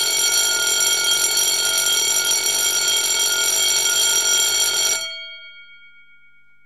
EB230W Fulleon Electronic Bell, EB230W Fulleon Factory Bell, EB230W Fulleon School Bell, EB230W Fulleon Security Bell
SOUND PREVIEW AVAILABLE
A unique patented electronic bell designed for use in fire, security and other signaling systems. The combination of a miniature solenoid with an integrated control circuit allows excellent sound coverage, minimum current consumption and increased reliability.